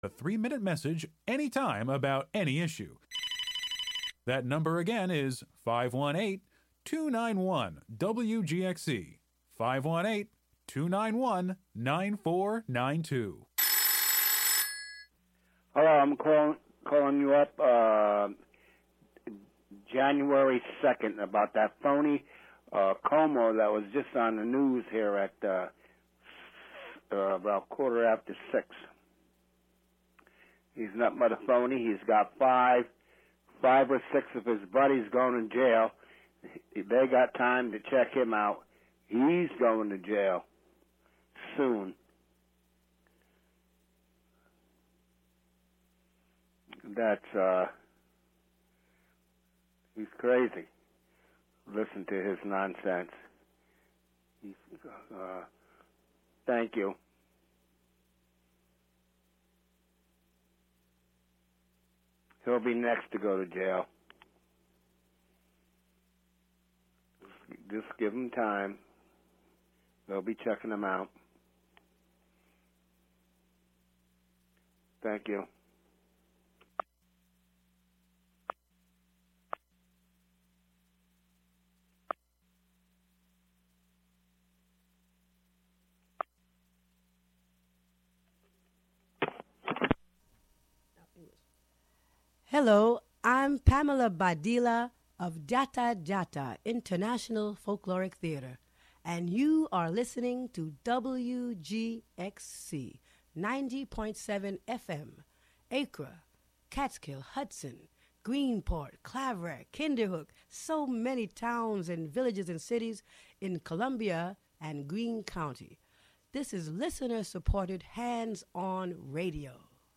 Hear the Congressional Report for NY19, and other local sounds, radio art, and more.